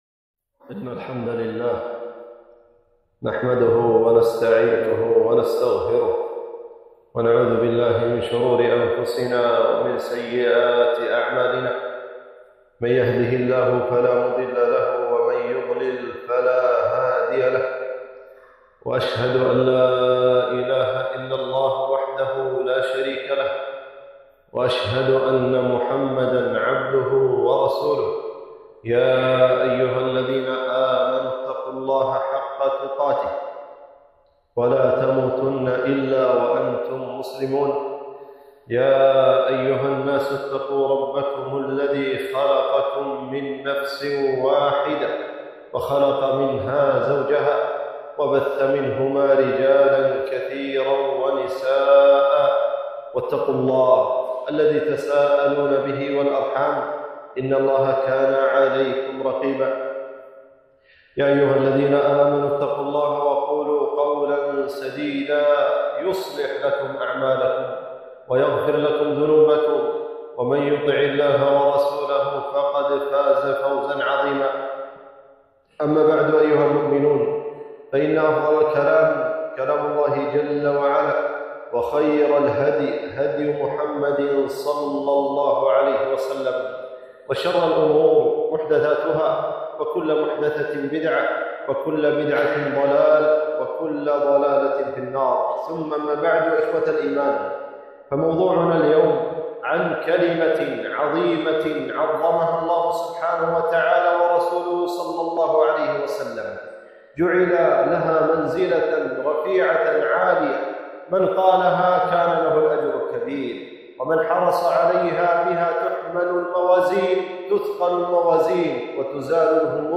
خطبة - فضل ( لا حول ولا قوة إلا بالله )